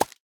Minecraft Version Minecraft Version 1.21.5 Latest Release | Latest Snapshot 1.21.5 / assets / minecraft / sounds / mob / armadillo / scute_drop2.ogg Compare With Compare With Latest Release | Latest Snapshot
scute_drop2.ogg